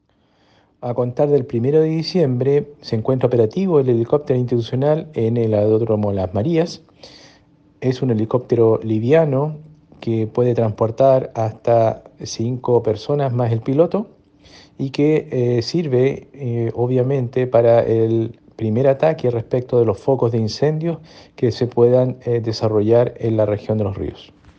Arnoldo-Shibar_-director-Conaf_-hito-helicoptero.mp3